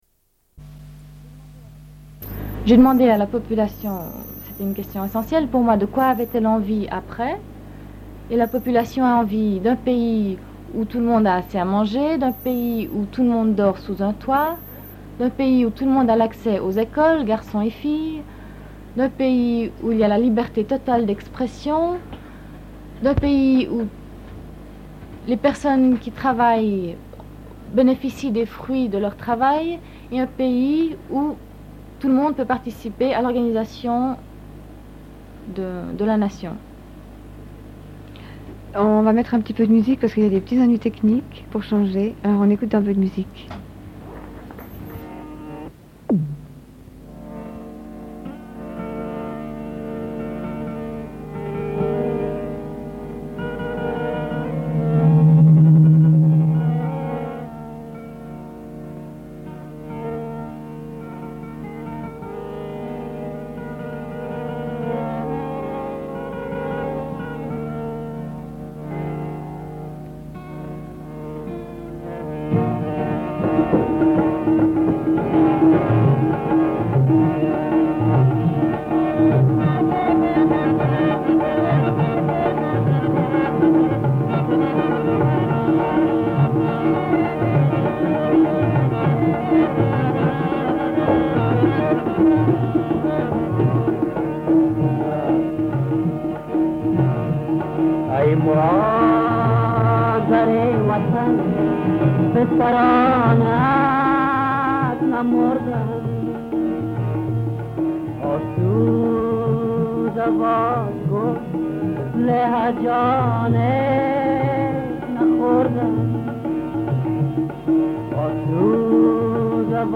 Une cassette audio, face A44:04
Suite de l'émission avec une personne membre du Comité genevois de soutien au peuple Afghan.
00:03:30 // Reprise de l'entretien.